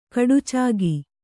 ♪ kaḍucāgi